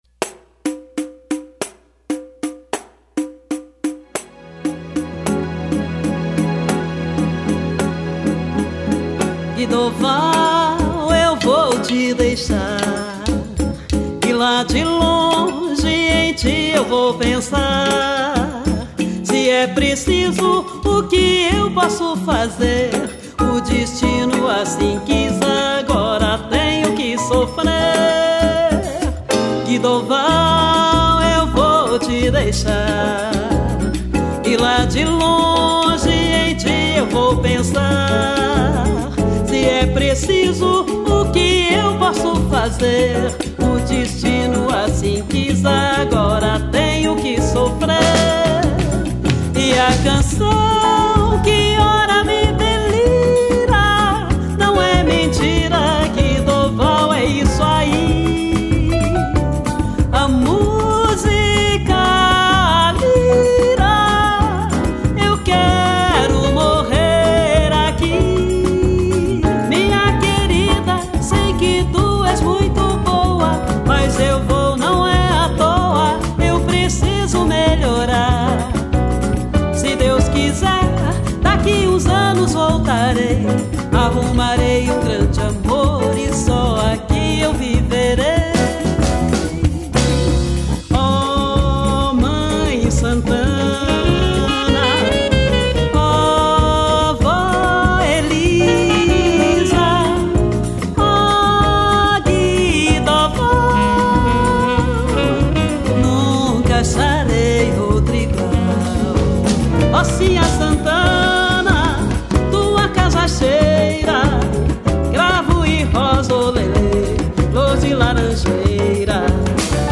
Voz
Violão
Baixo
Bateria
Percussão
Sax Tenor
Teclados